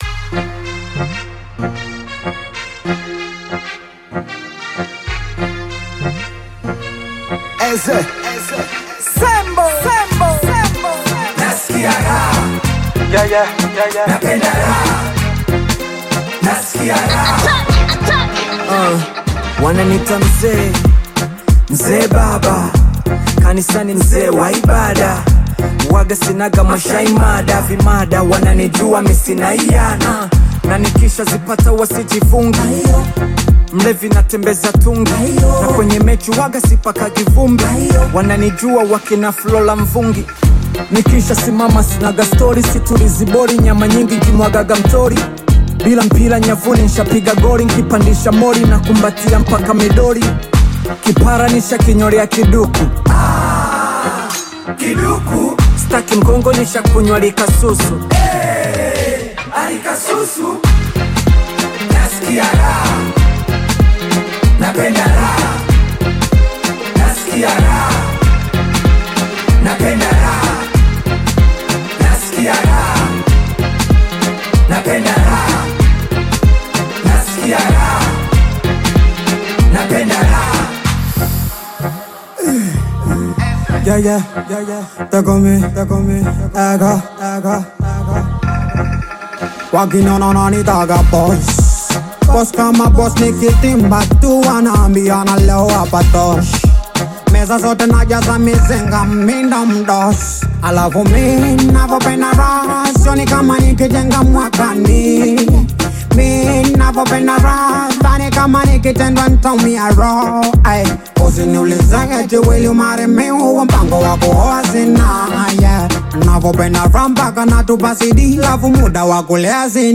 Bongo Flava music track
Tanzanian Bongo Flava artist